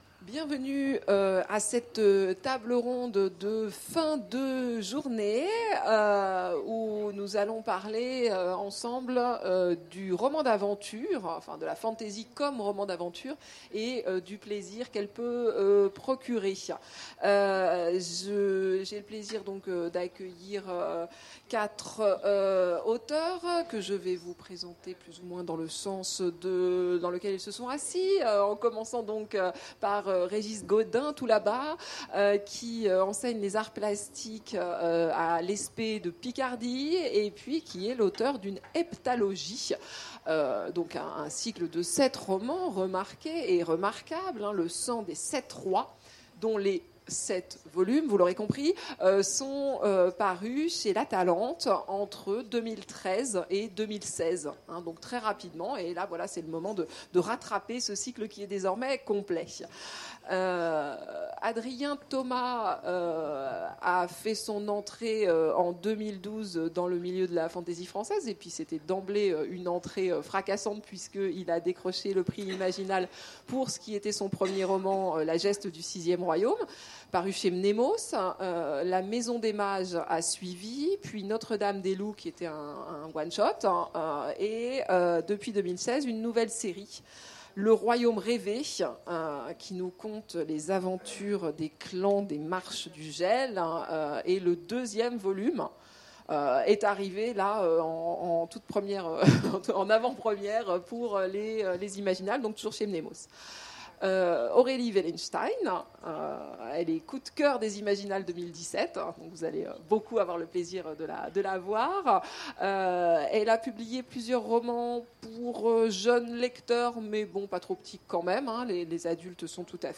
Mots-clés Aventure Conférence Partager cet article